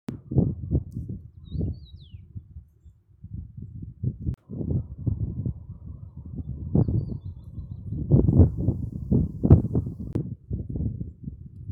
чечевица, Carpodacus erythrinus
Administratīvā teritorijaAlūksnes novads
СтатусПоёт
Примечанияdziedāja aptuveni 2-3 minūtes